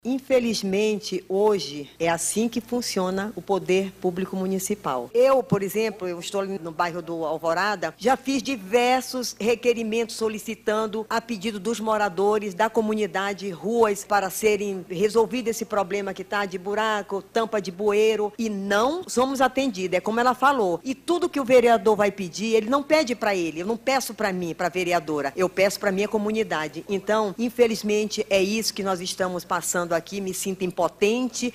A Câmara Municipal de Manaus – CMM realizou nesta quarta-feira 17/04, uma Tribuna Popular para receber as demandas dos moradores da zona Centro-Oeste da capital amazonense.
Após as denúncias, a vereadora Glória Carrate, do PSB, acolheu as demandas da comunidade e disse que a falta de respostas, por parte dos órgãos municipais, é uma realidade que os parlamentares de oposição também enfrentam.